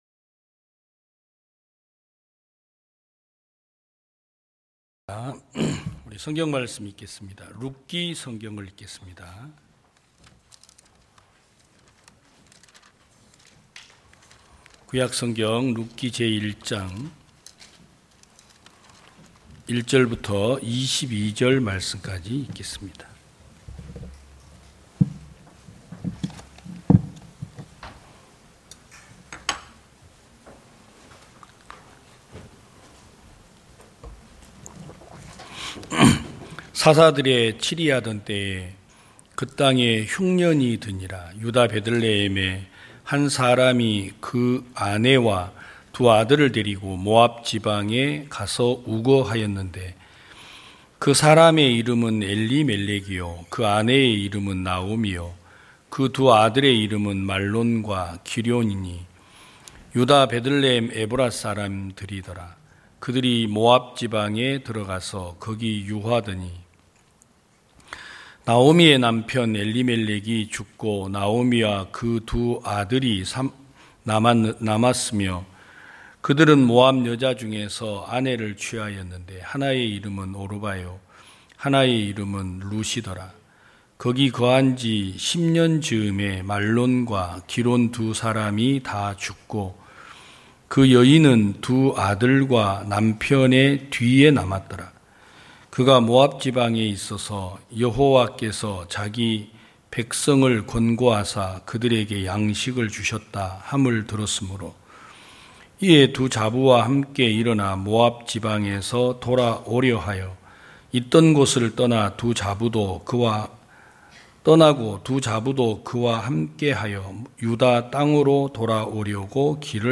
2022년 09월 25일 기쁜소식부산대연교회 주일오전예배
성도들이 모두 교회에 모여 말씀을 듣는 주일 예배의 설교는, 한 주간 우리 마음을 채웠던 생각을 내려두고 하나님의 말씀으로 가득 채우는 시간입니다.